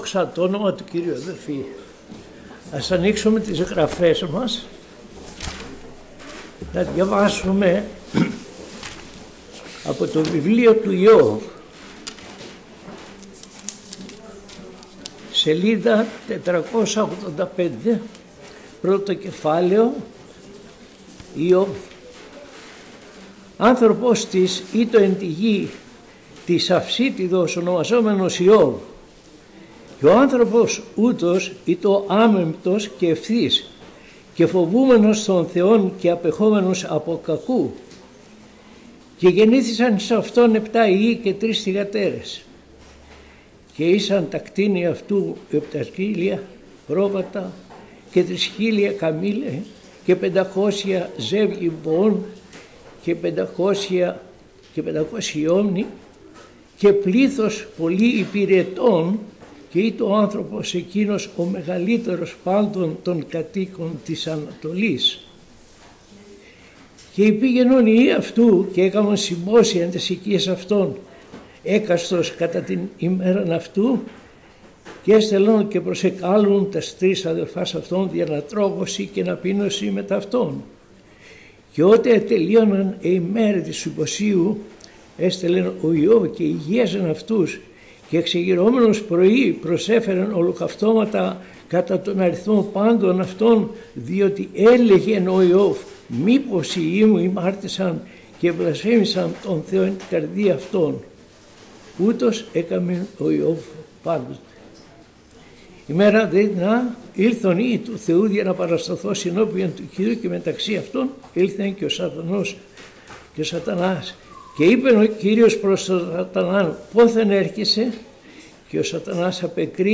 Κήρυγμα Κυριακής